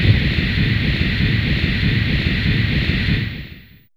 Index of /m8-backup/M8/Samples/Fairlight CMI/IIe/27Effects4